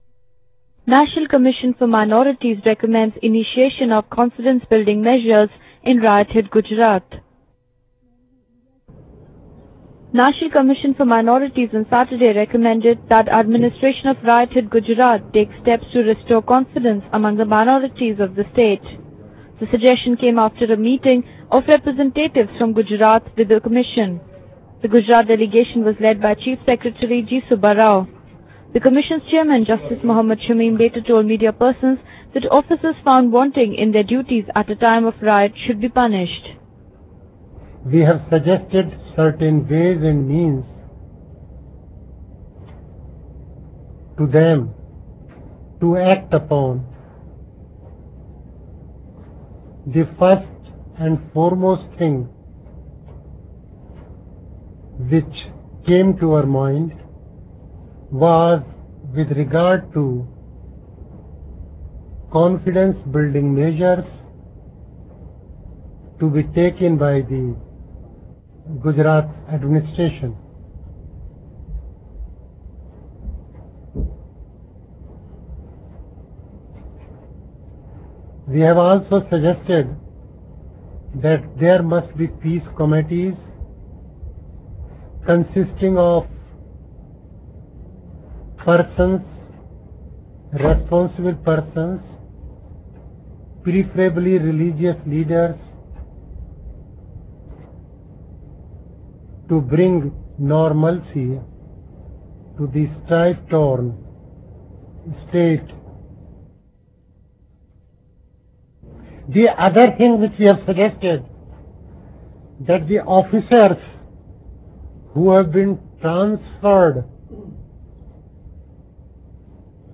Justice Mohammed Shamim, Chairman of NCM, Mr Tarlochan Singh, Vice-Chairman, and Lt-Gen (retd) A. M. Sethna during a Press conference Justice Mohammed Shamim, Chairman of NCM (centre), Mr Tarlochan Singh, Vice-Chairman, and Lt-Gen (retd) A. M. Sethna during a Press conference of the National Commission for Minorities on Gujarat disturbances in New Delhi on Saturday.